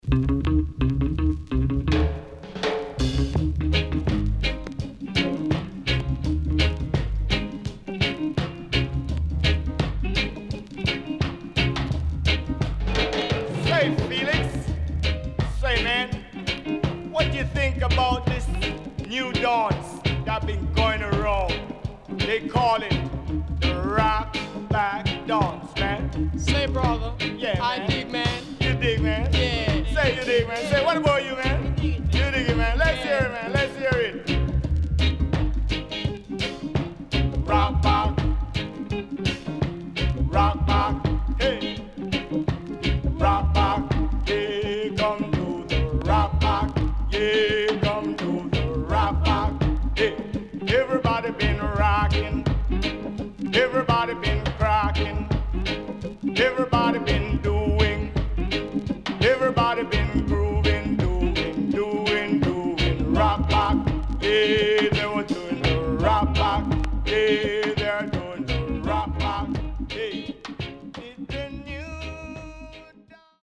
HOME > REGGAE / ROOTS
SIDE B:少しノイズ入りますが良好です。